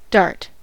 dart: Wikimedia Commons US English Pronunciations
En-us-dart.WAV